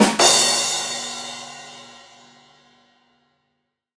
drumrollEnd.ogg